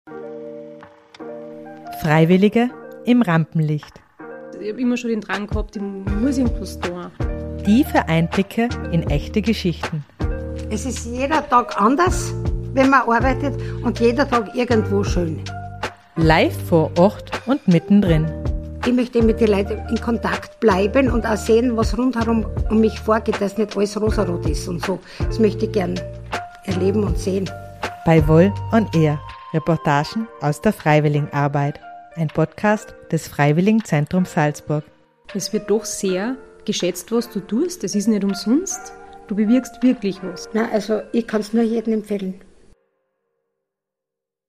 direkt vor Ort, mit viel Interaktion und spannenden Gesprächen.